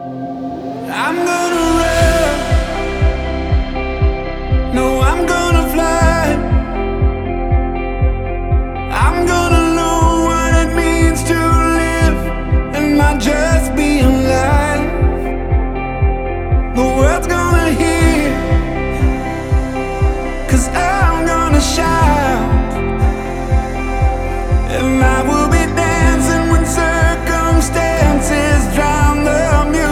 • Christian